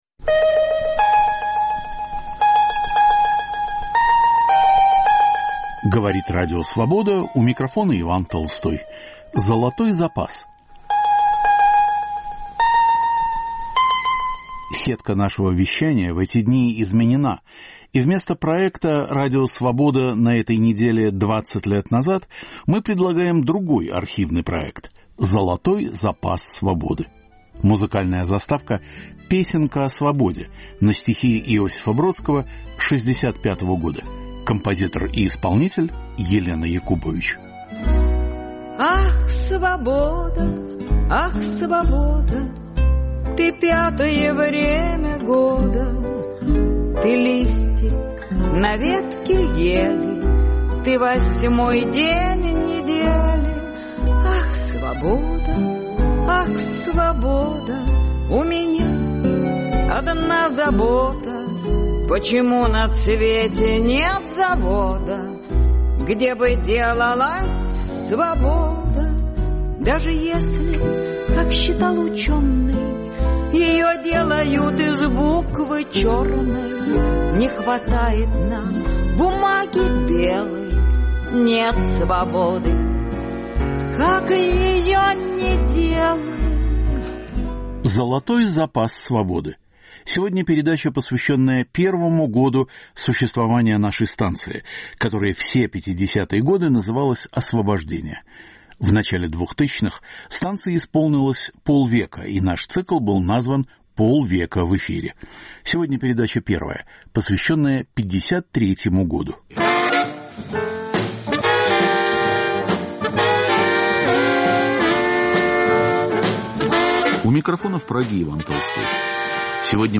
Позывной сигнал - "Гимн России" Гречанинова и многое другое.